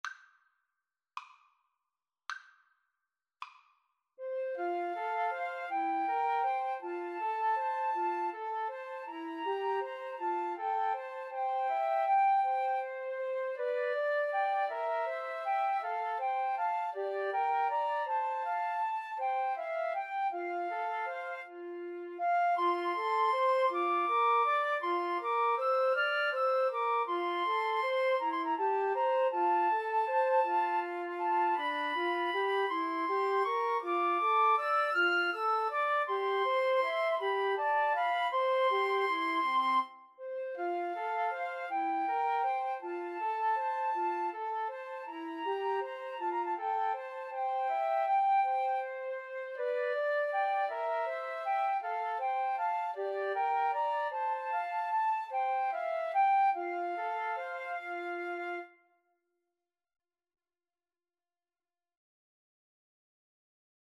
Andante
6/8 (View more 6/8 Music)
Flute Trio  (View more Intermediate Flute Trio Music)